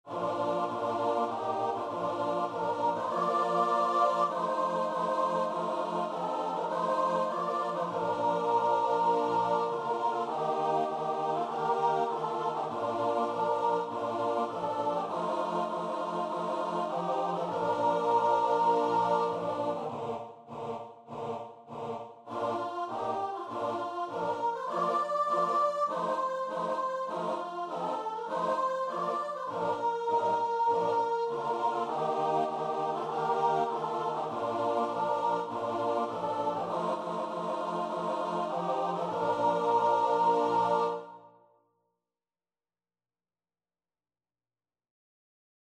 Free Sheet music for Choir
4/4 (View more 4/4 Music)
Bb major (Sounding Pitch) (View more Bb major Music for Choir )
Choir  (View more Intermediate Choir Music)
Christian (View more Christian Choir Music)